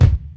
Dusty Kick 02.wav